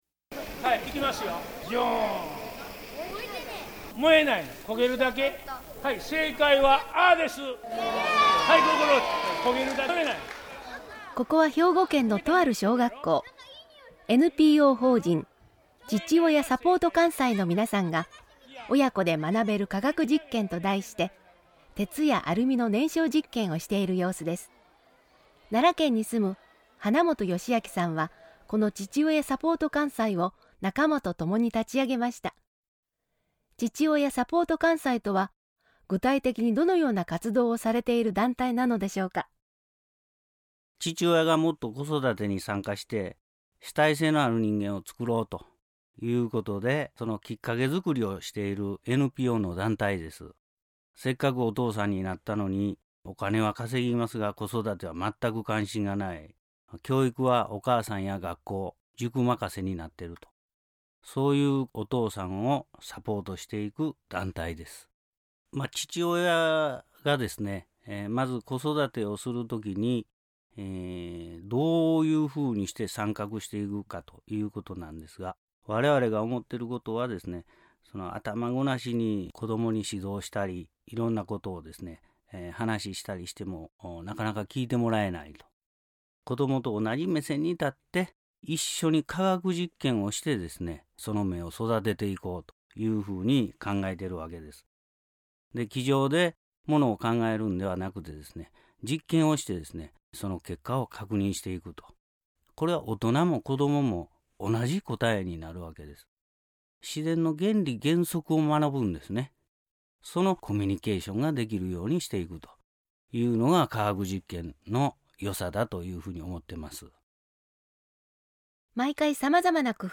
●信者さんのおはなし